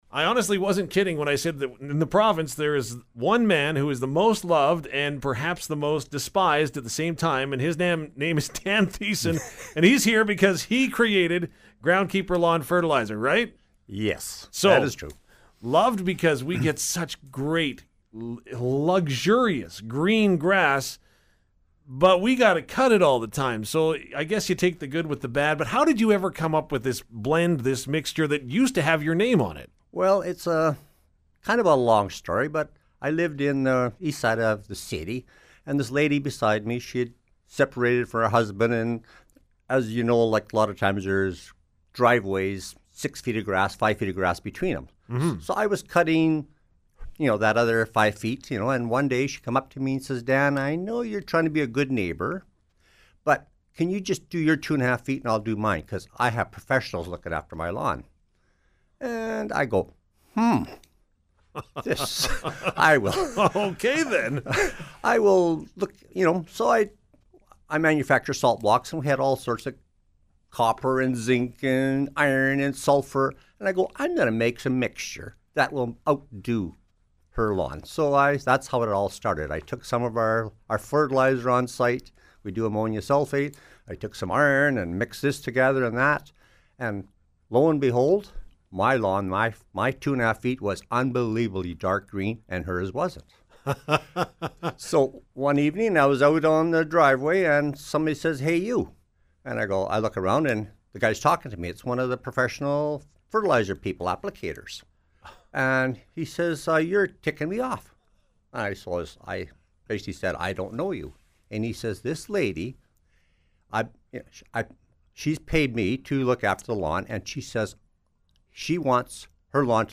2022-groundkeeper-interview.mp3